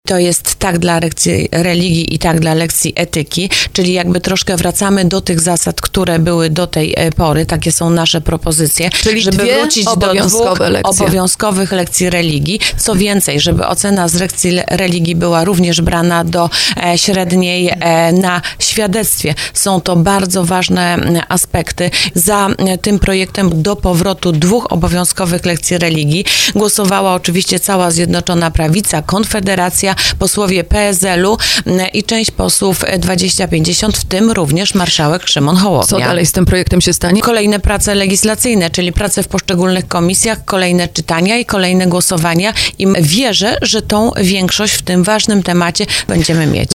Poseł PiS Anna Pieczarka, która była gościem programu Słowo za słowo powiedziała, że projekt Stowarzyszenia Katechetów Świeckich odzwierciedla oczekiwania Polaków.